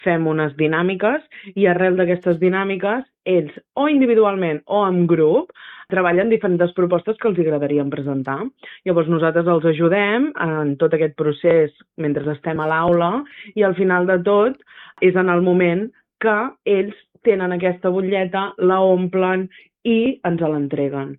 Cada proposta pot arribar fins als 4.000 euros i, si al final les iniciatives guanyadores no consumeixen tota la partida, l’Ajuntament es compromet a explorar altres projectes per aprofitar els diners restants. Així ho ha explicat la regidora de Participació, Mariceli Santarén, a Ràdio Calella TV: